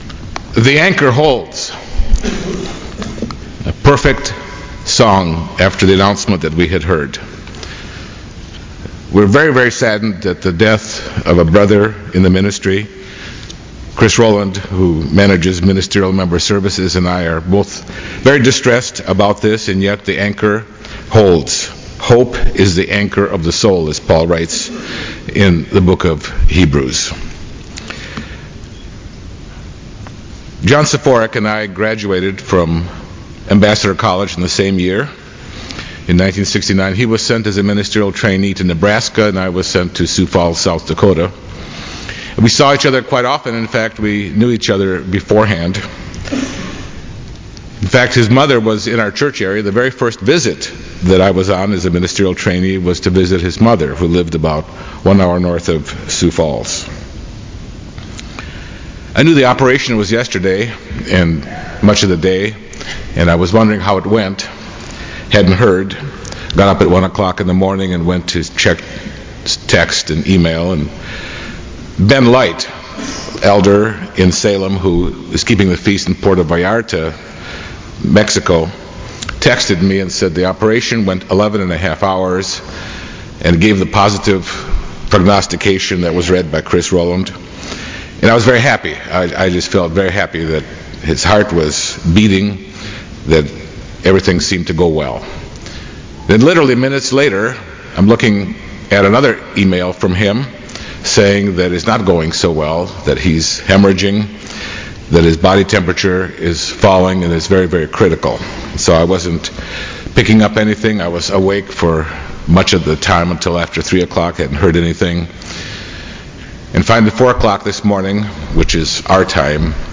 Feast of Tabernacles Sermon Transcript This transcript was generated by AI and may contain errors.